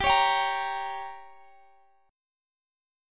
Listen to Gdim strummed